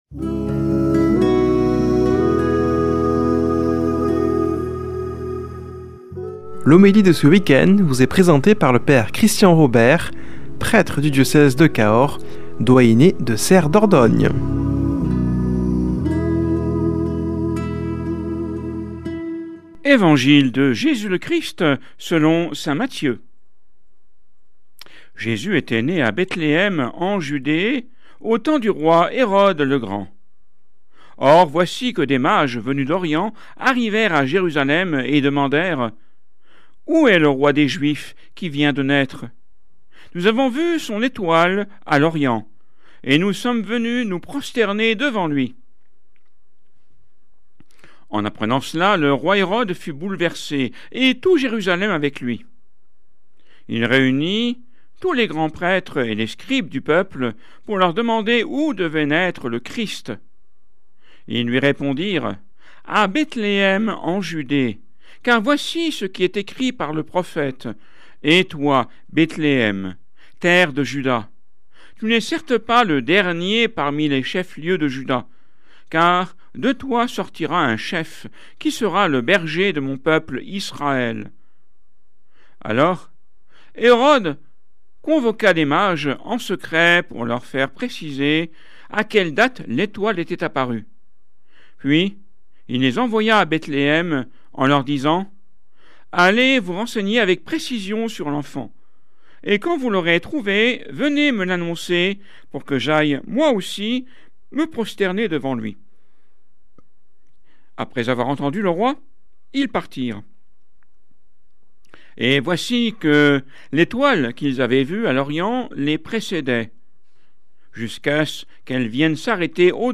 Homélie du 03 janv.